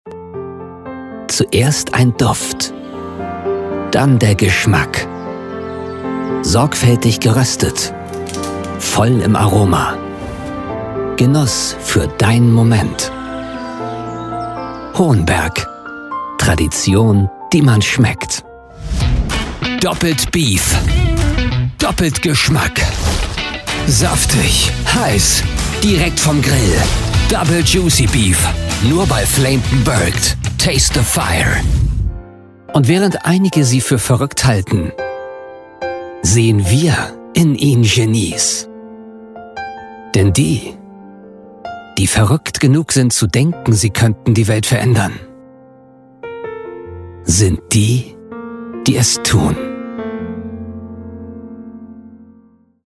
Conversacional
Confiable
Amistoso